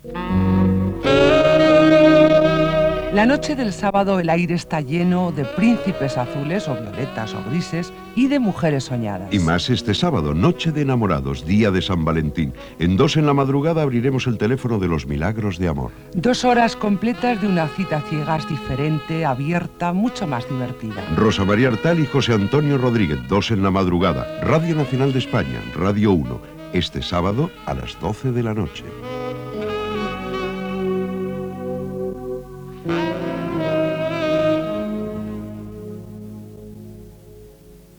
Promoció del programa del dia de Sant Valentí